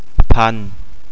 one thousand - "Paan"